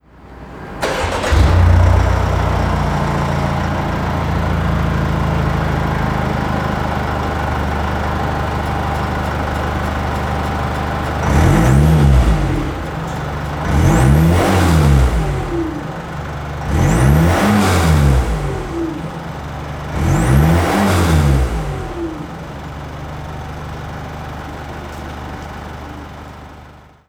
デリカD:5（ディーゼル）｜マフラーサウンド公開